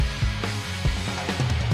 音源に絶対入らないであろう声が聞こえるのです！！！
<加工していない元の音源＞
問題の声は「4拍目少し前に入っている「ハイ！」という声」